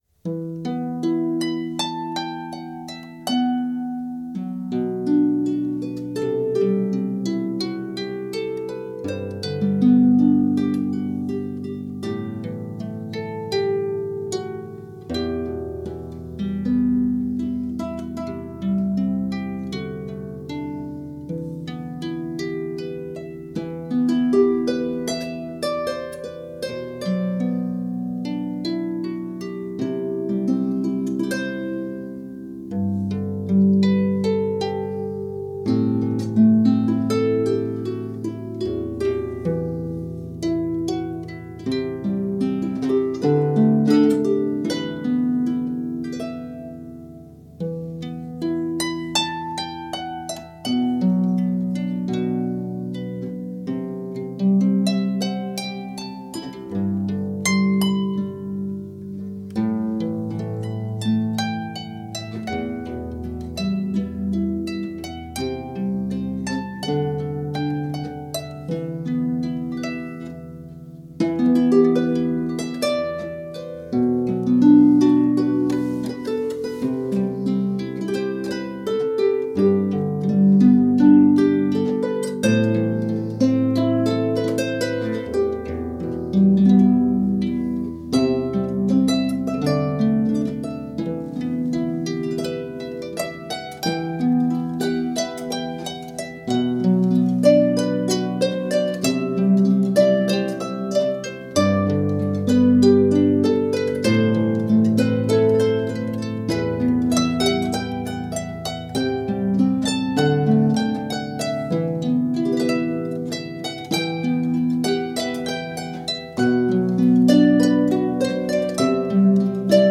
teilweise im Duo, teilweise solo.